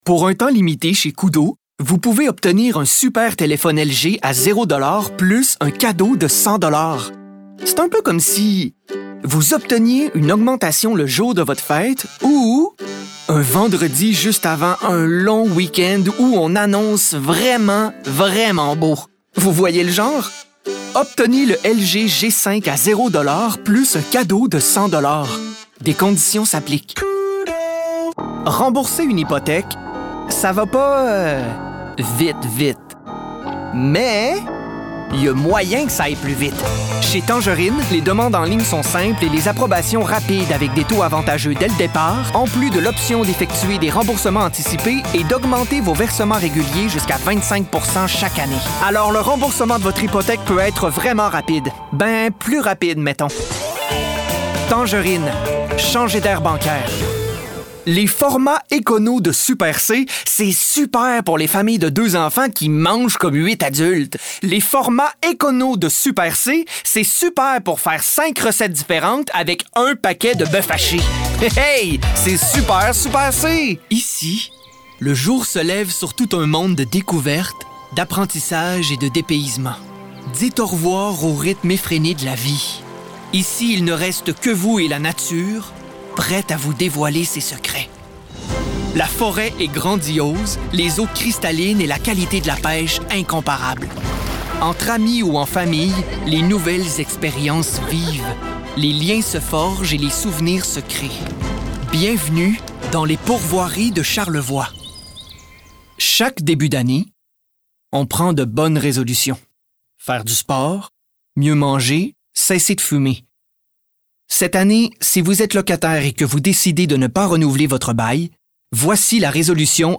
voix
Narration - Français - Voix